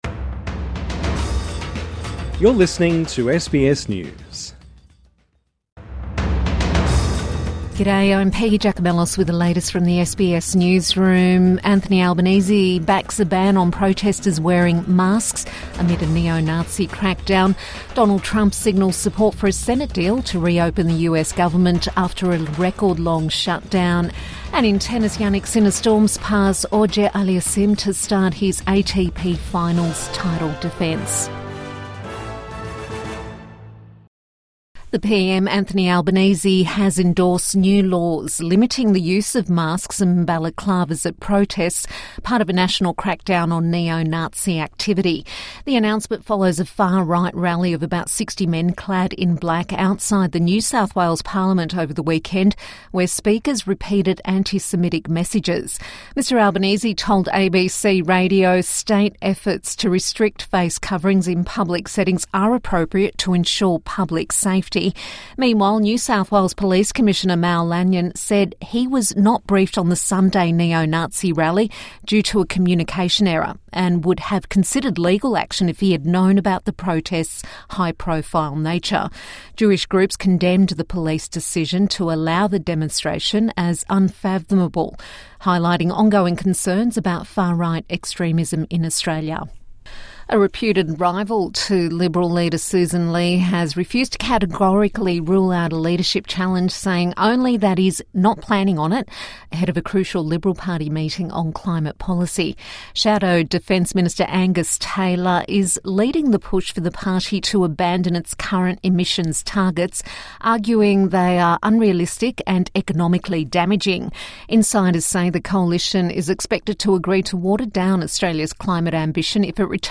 Government backs ban on face masks at political protests | Midday News Bulletin 11 November 2025